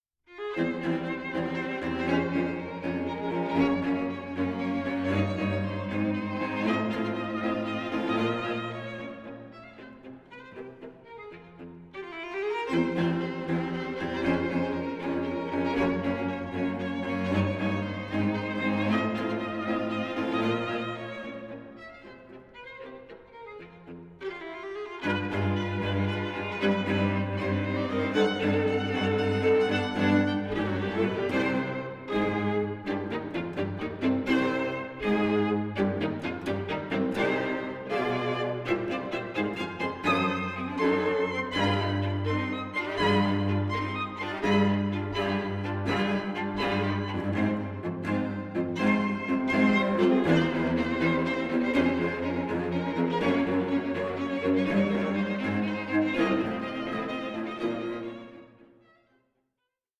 String Quartet No. 6 in F Minor, Op. 80
recorded live at Kohl Mansion